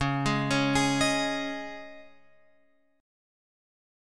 C#sus2 chord {9 6 6 8 9 x} chord